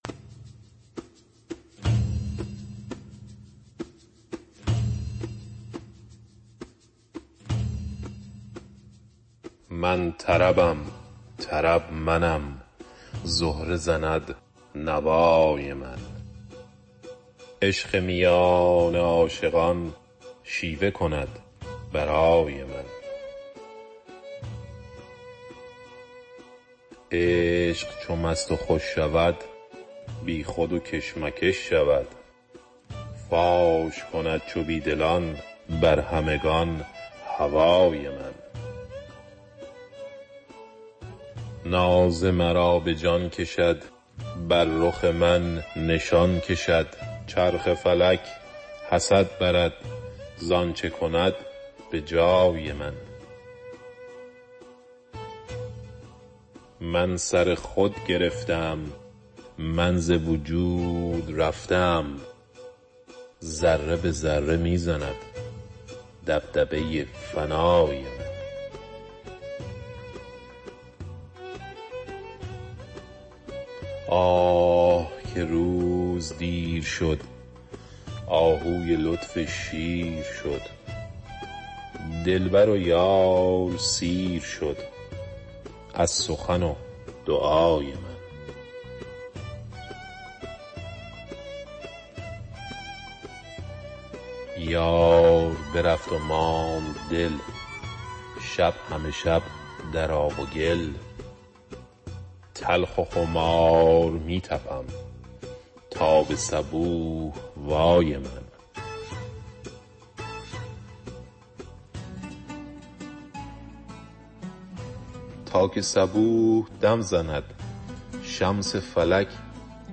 شعر خوانى